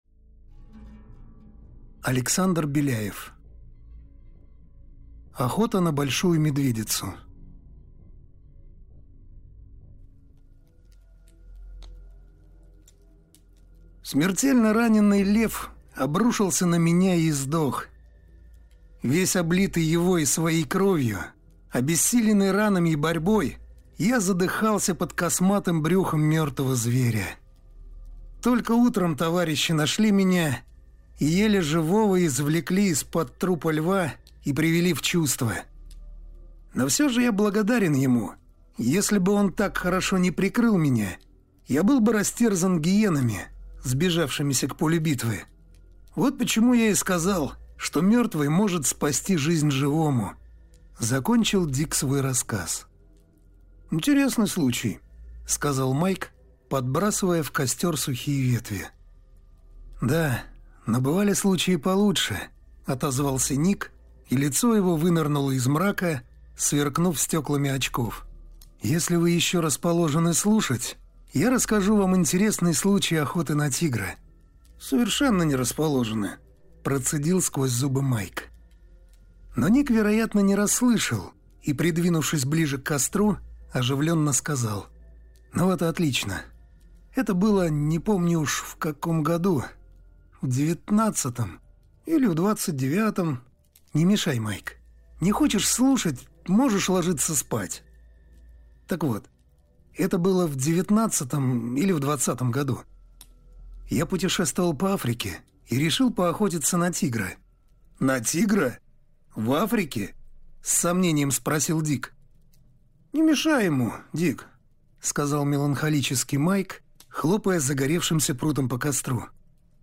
Аудиокнига Охота на Большую Медведицу | Библиотека аудиокниг